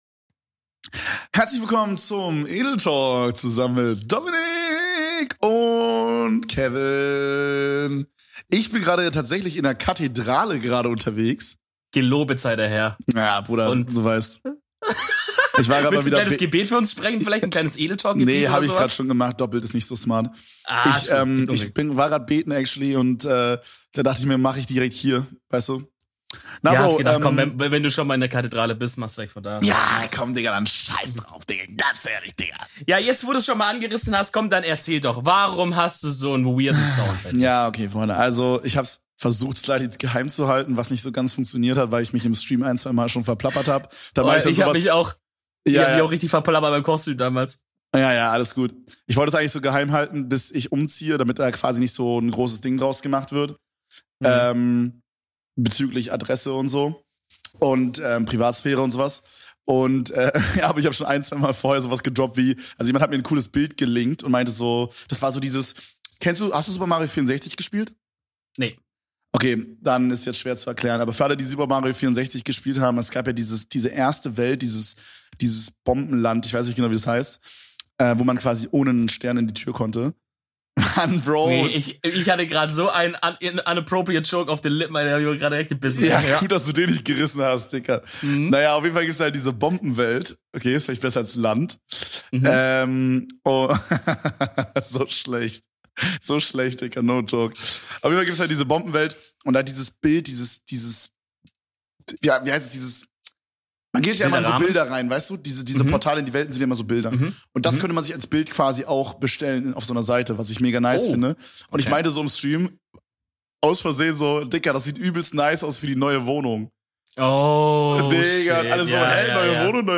Wir bitten um Gottes Willen den Hall zu entschuldigen, der Herrgott mag seine Häuser nun mal groß und luftig wa.